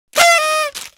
popper.mp3